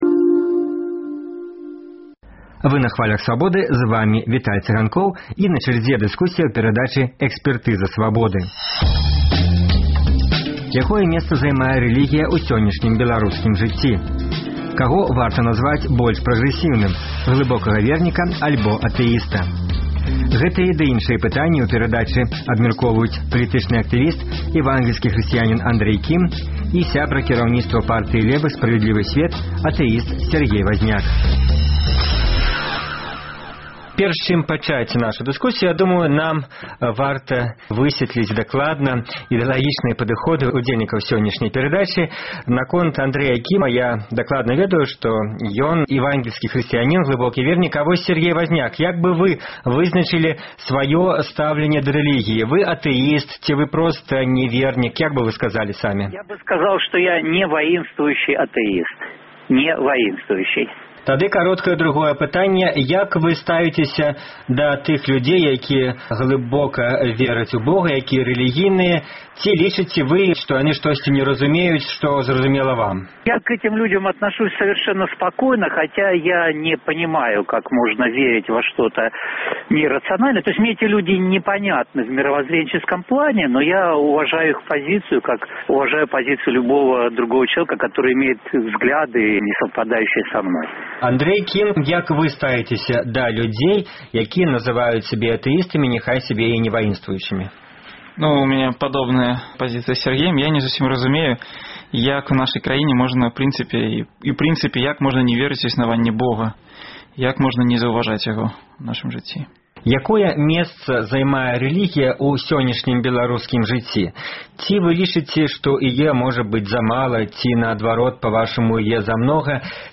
абмяркоўваюць палітычны актывіст, эвангельскі хрысьціянін